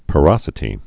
(pə-rŏsĭ-tē, pô-)